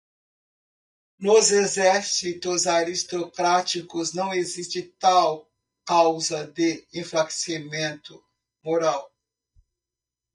Read more Adj Noun Noun Frequency B2 Pronounced as (IPA) /moˈɾaw/ Etymology Borrowed from Latin mōrālis In summary Learned borrowing from Latin mōrālis.